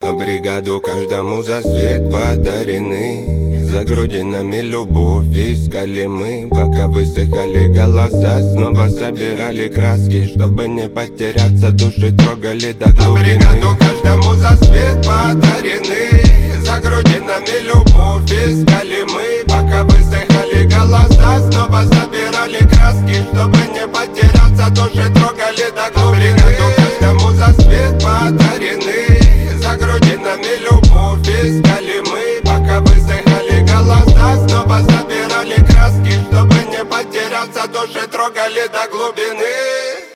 хип-хоп
рэп , дуэт
регги